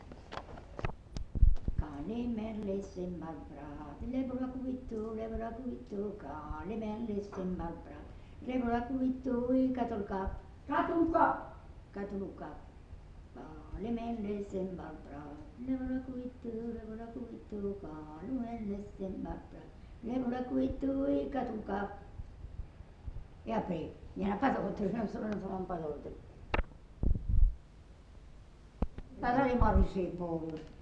Lieu : Mas-Cabardès
Genre : chant
Effectif : 1
Type de voix : voix de femme
Production du son : chanté
Danse : polka